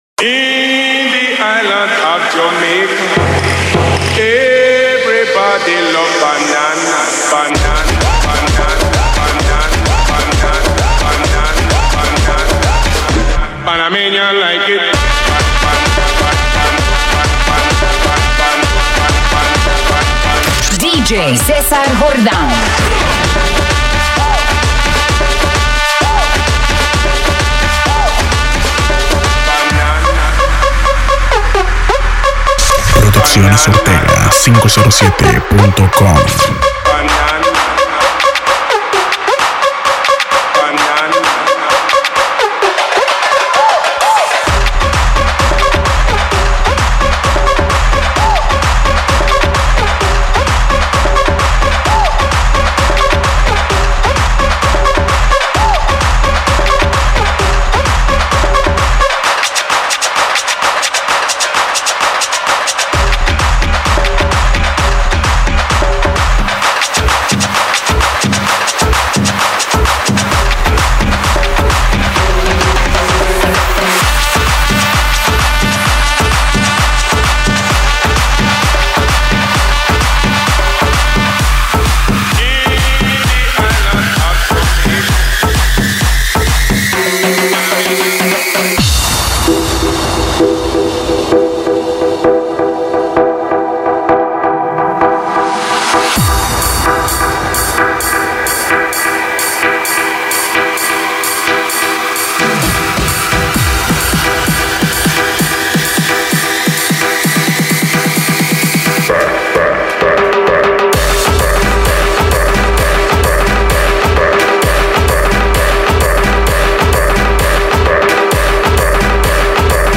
Electrónica | Mixes